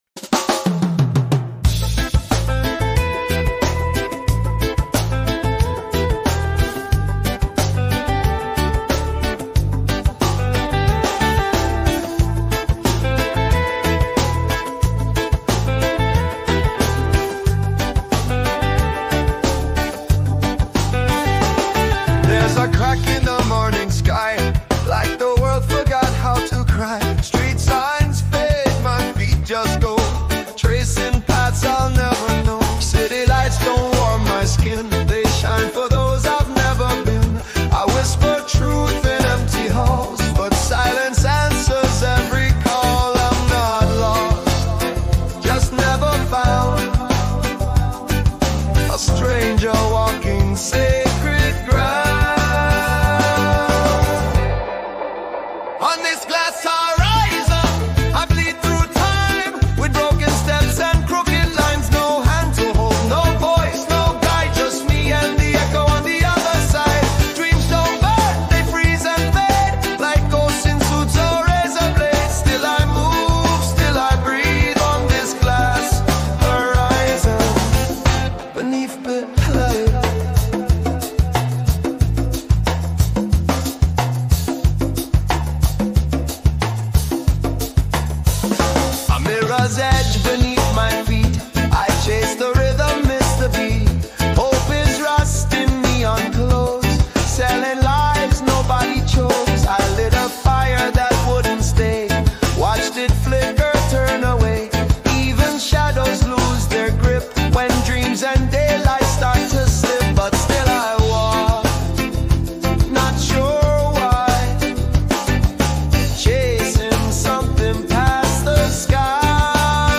Reggae Chill Song About Loneliness